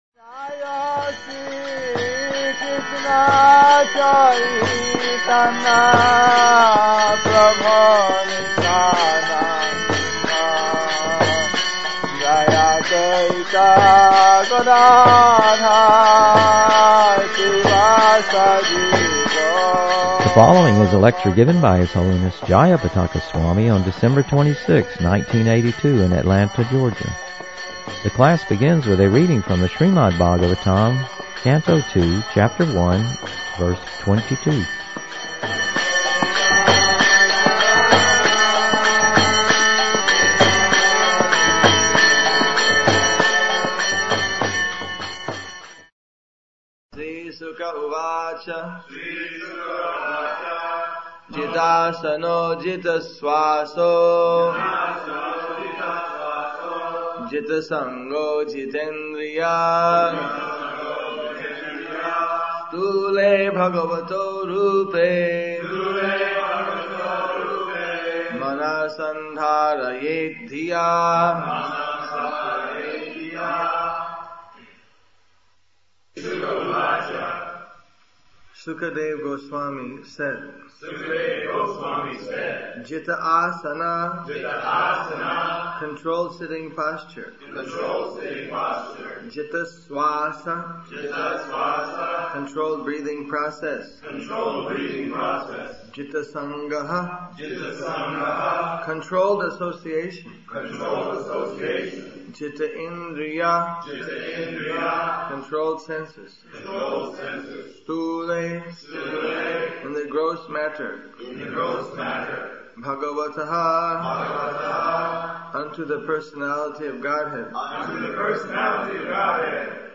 The class begins with a reading from the Srimad-Bhagavatam, Canto 2, Chapter 1, Verse 22.